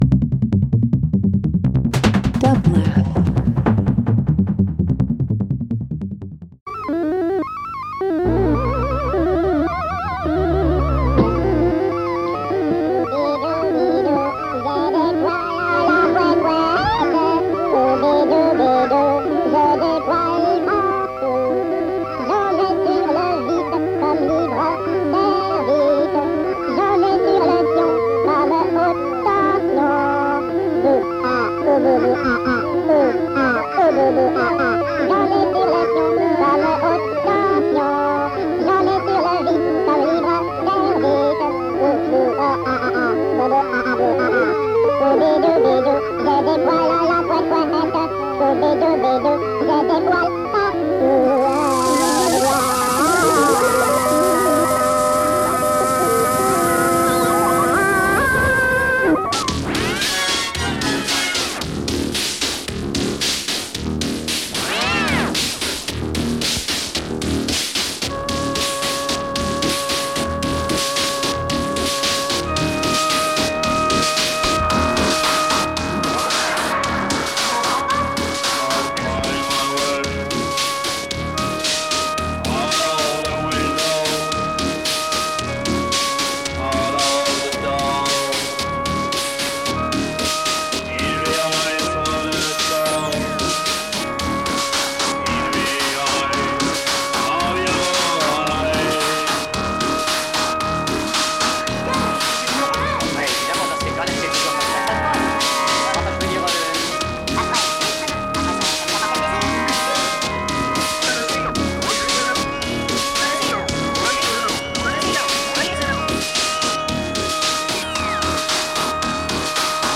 Ambient Avant-Garde Electronic